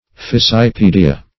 Fissipedia \Fis`si*pe"di*a\, n. pl. [NL., fr. L. fissus (p. p.